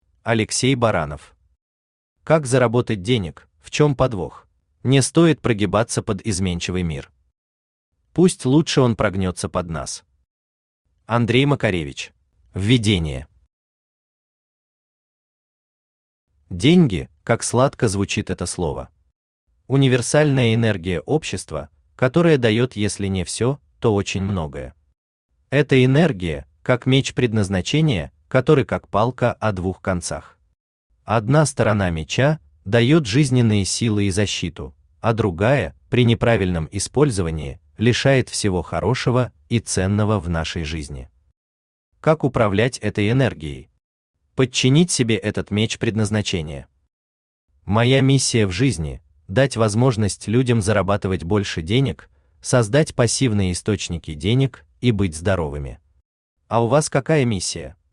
Аудиокнига Как заработать денег – в чём подвох?
Автор Алексей Игоревич Баранов Читает аудиокнигу Авточтец ЛитРес.